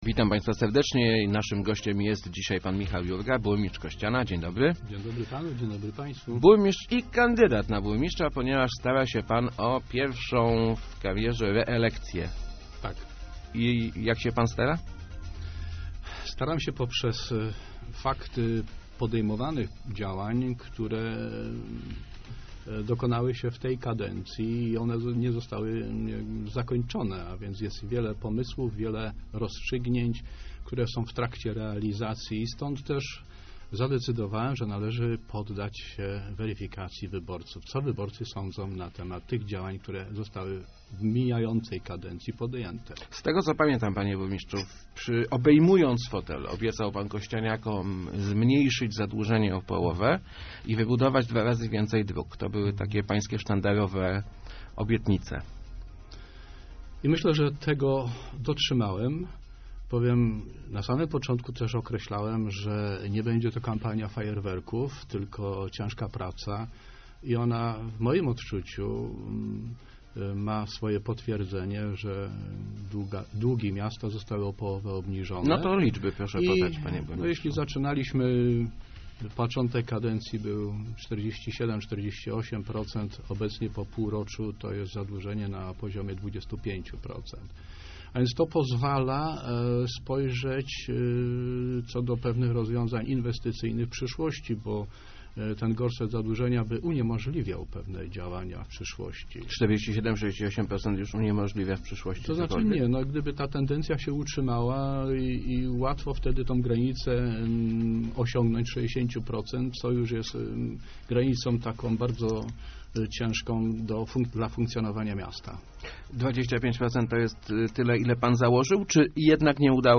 Park Morawskiego czy Łazienki to nie jest mój pomysł, tylko wsłuchujemy się w postulaty mieszkańców - mówił w Rozmowach Elki burmistrz Kościana Michał Jurga.